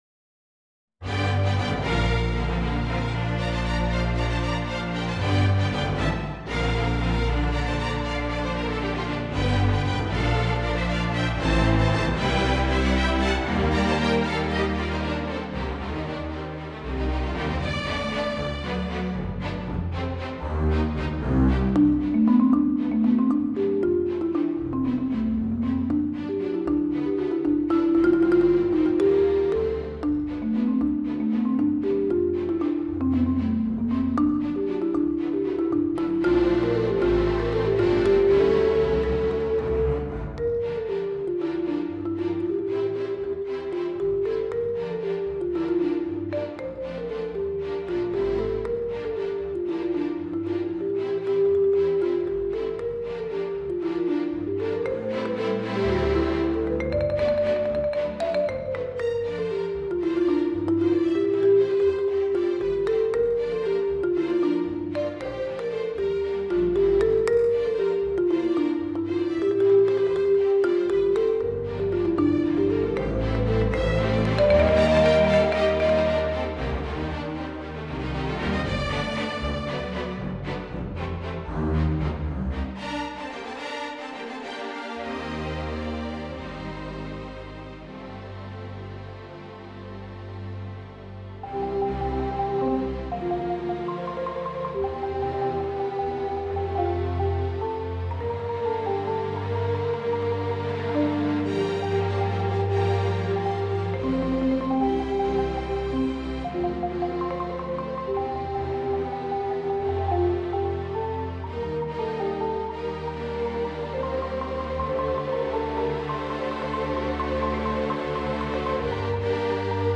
Voicing: Marimba and String Orchestra